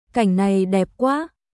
カイン ナイ デップ クア🔊